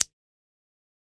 Perc 3.wav